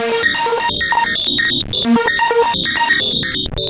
Cyclic Error Bb 130.wav